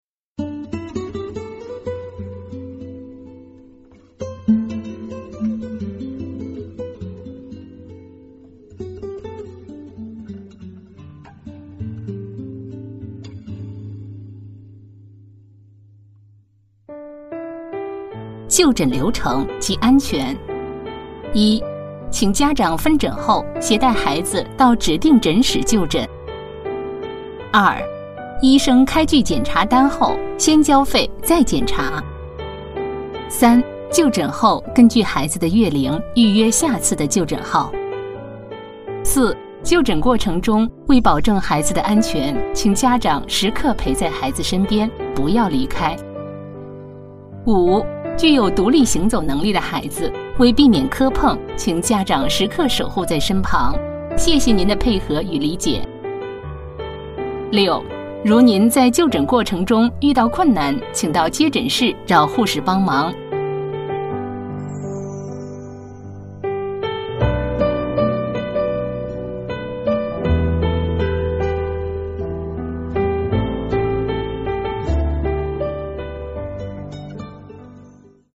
配音风格： 大气 讲述 活力 浑厚